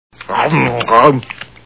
The Simpsons [Homer] Cartoon TV Show Sound Bites
Homer eating.
homer_eat2.wav